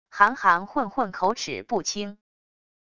含含混混口齿不清wav音频